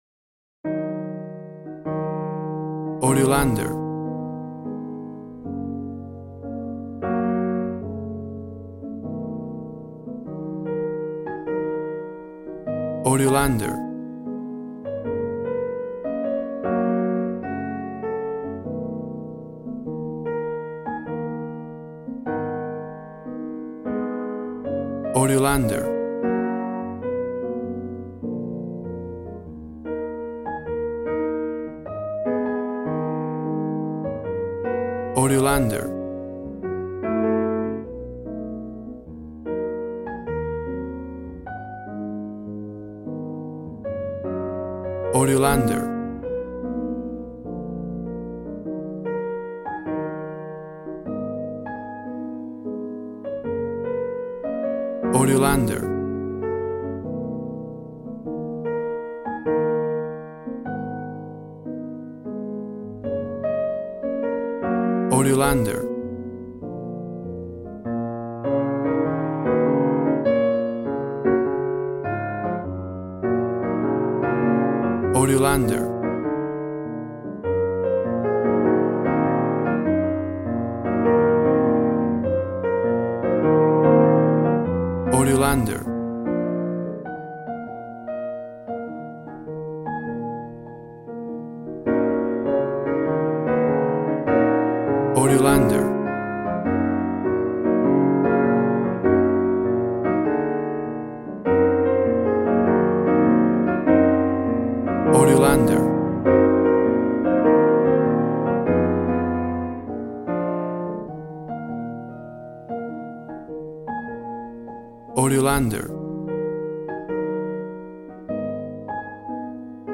Smooth jazz piano mixed with jazz bass and cool jazz drums.
WAV Sample Rate 16-Bit Stereo, 44.1 kHz
Tempo (BPM) 100